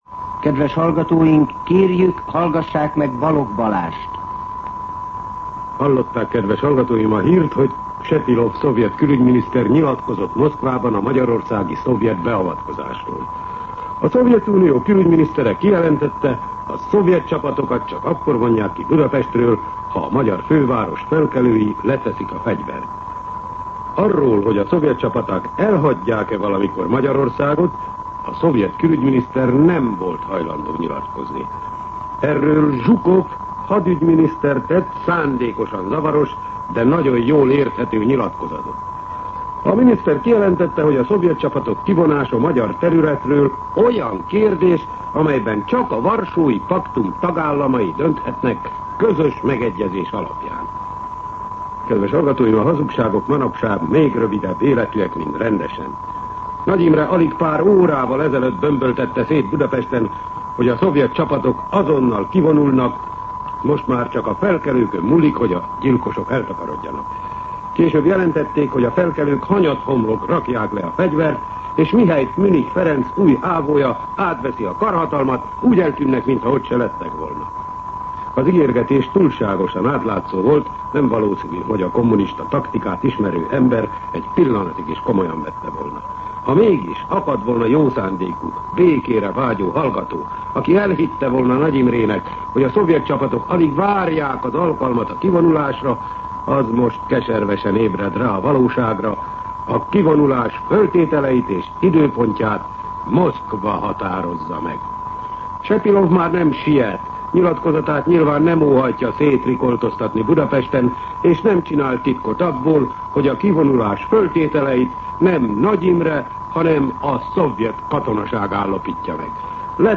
Rendkívüli kommentár
MűsorkategóriaKommentár